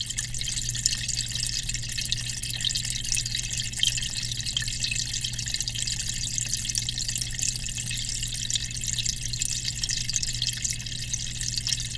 aquarium_small.L.wav